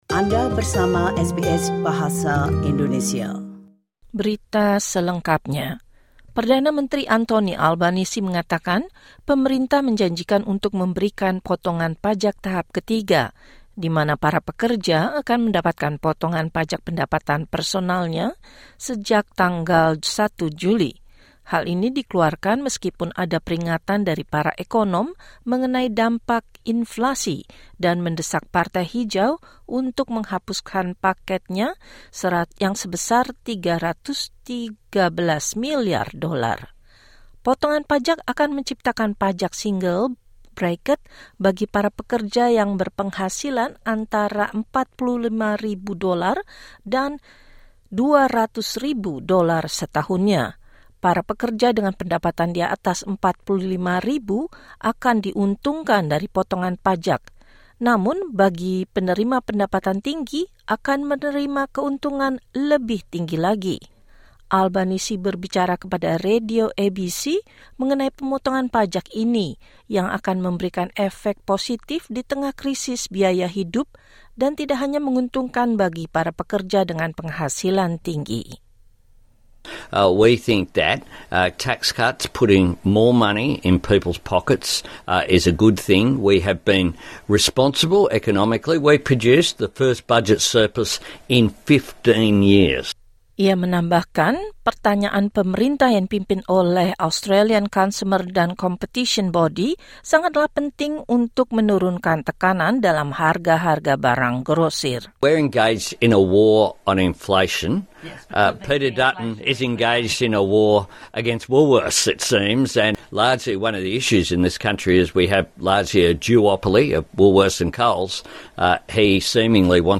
Berita terkini SBS Audio Program Bahasa Indonesia – 19 Jan 2024